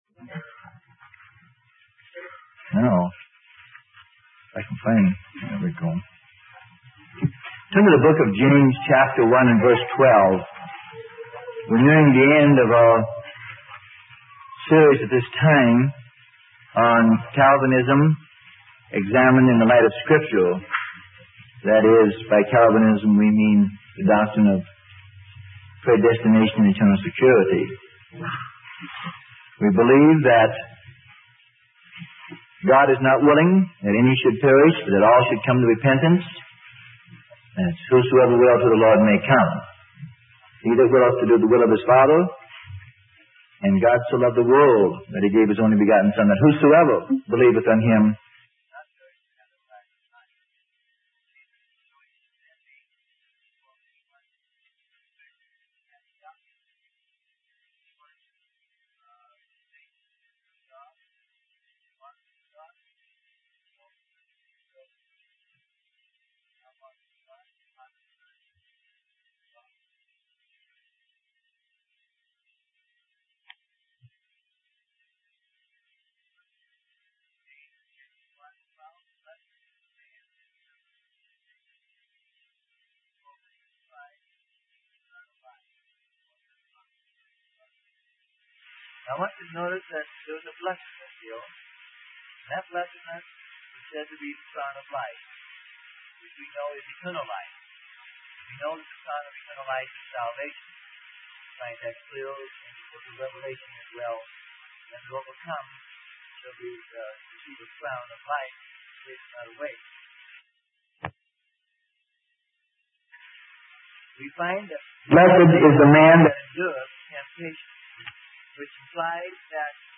Sermon: Calvinism - Part 11 - Freely Given Online Library